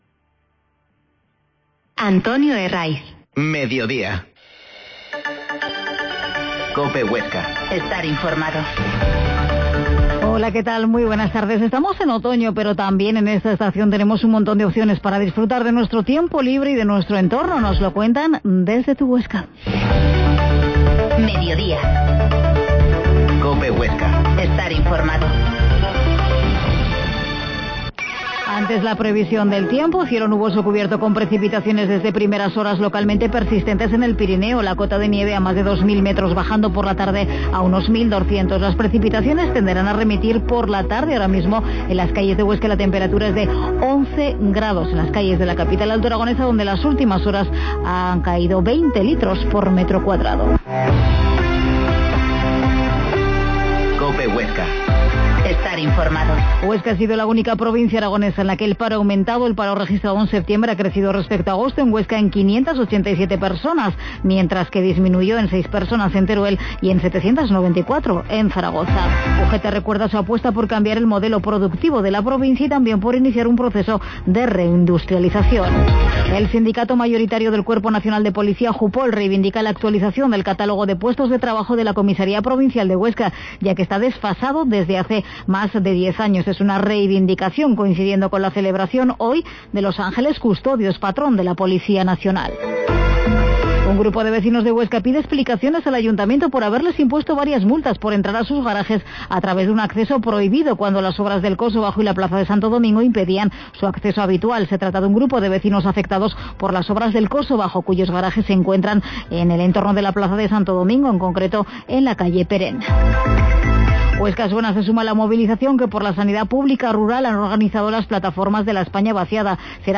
La Mañana en COPE Huesca - Magazine